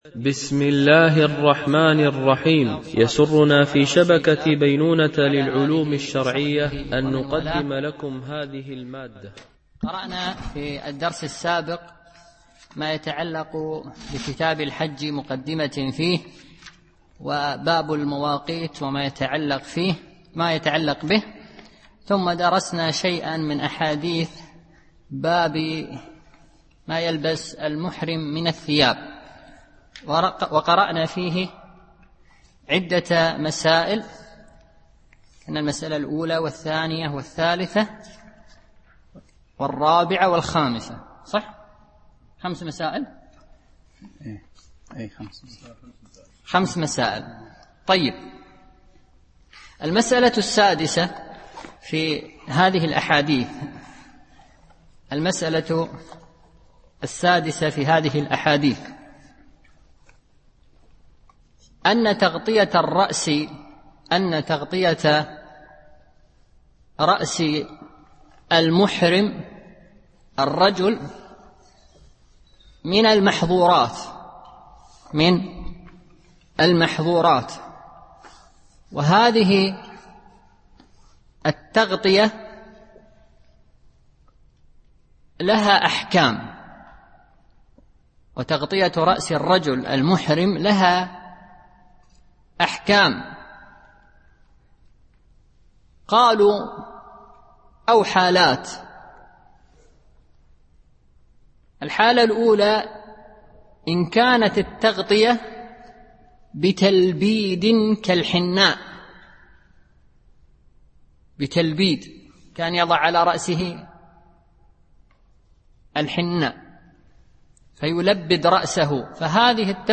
شرح عمدة الأحكام - الدرس 57 (الحديث 218 ـ 221)